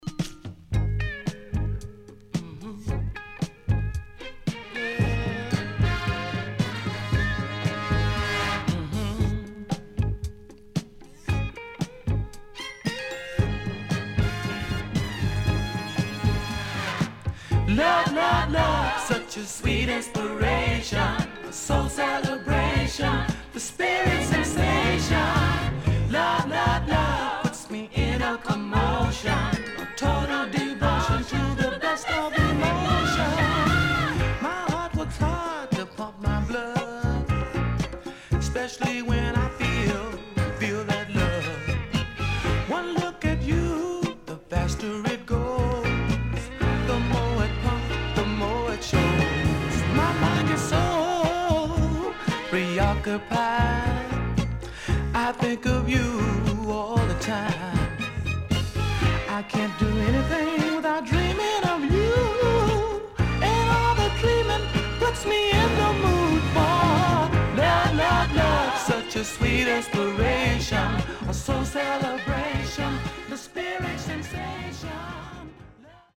HOME > SOUL / OTHERS
CONDITION SIDE A:VG(OK)〜VG+
SIDE A:出だし数発プチパチノイズ入ります。